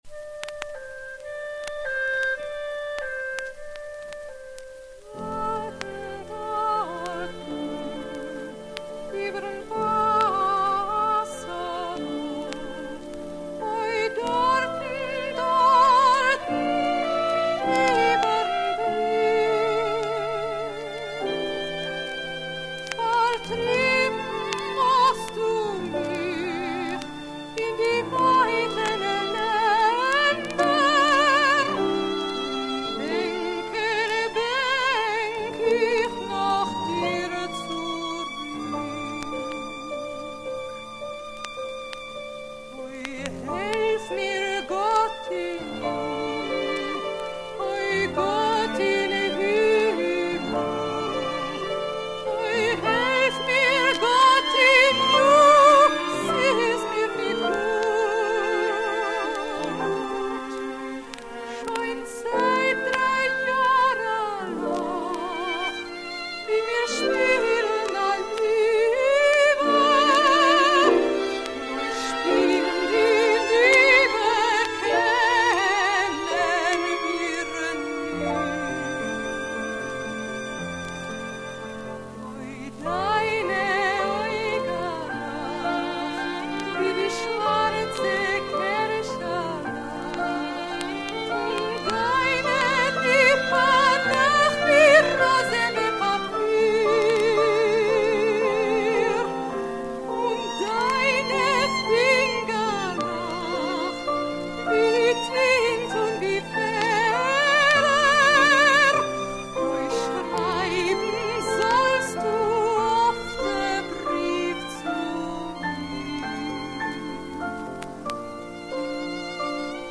with an orchestra